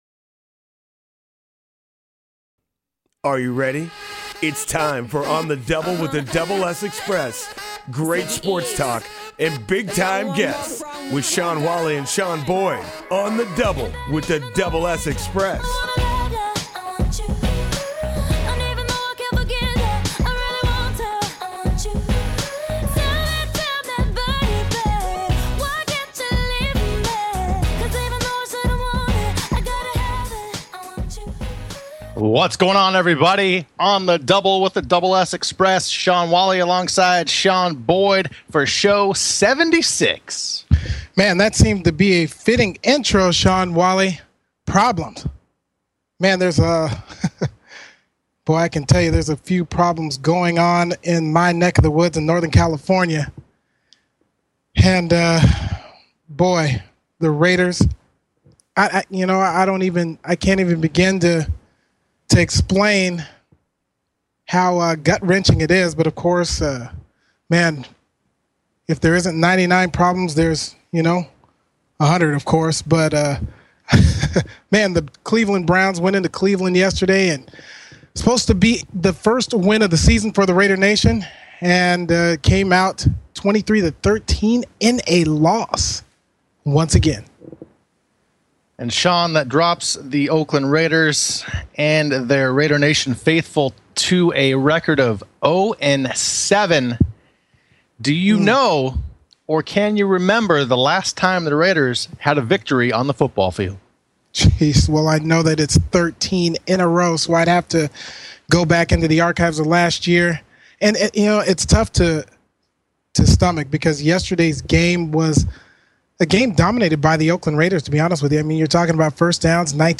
On the Double with The Double S Xpress sports talk show; Sports Broadcasting; Play-by-Play; Analysis; Commentary; Insight; Interviews; Public Address Mission: To provide the best play-by-play & analysis of all sports in the broadcasting world.